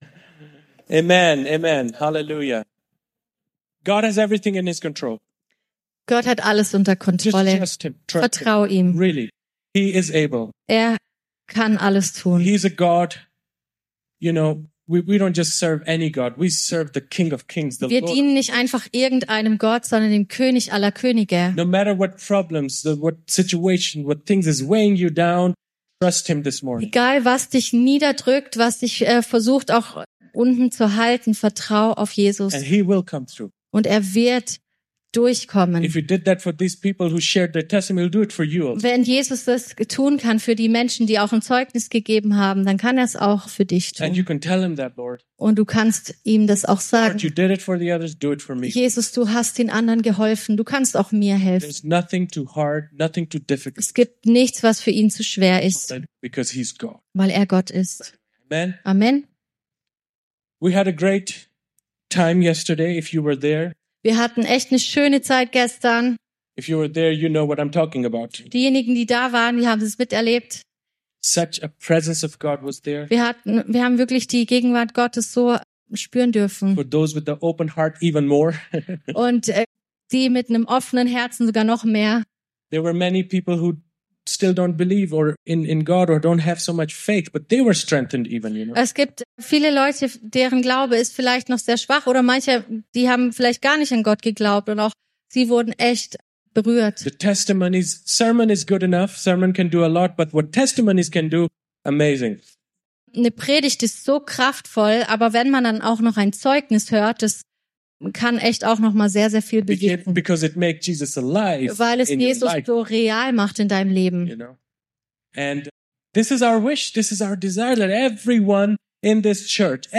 Predigt
im Christlichen Zentrum Villingen-Schwenningen.